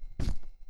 hitFabric1.wav